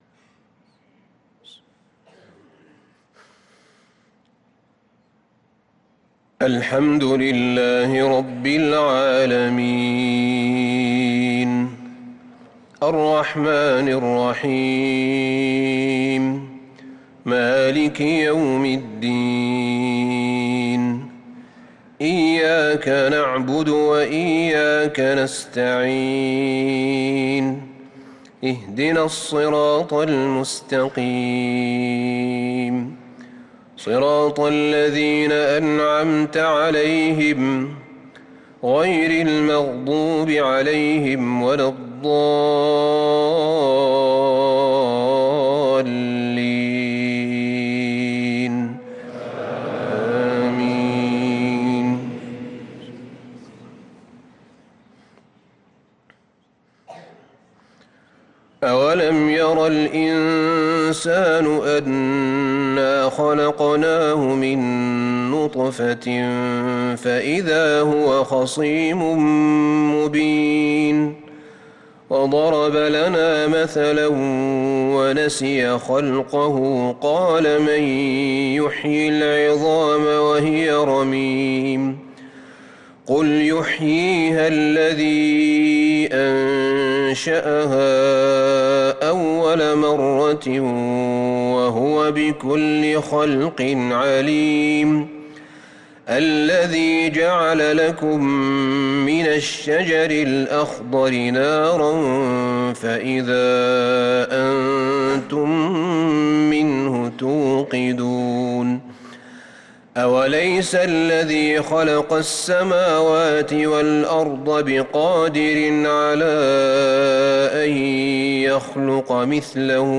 صلاة العشاء للقارئ أحمد بن طالب حميد 21 رجب 1445 هـ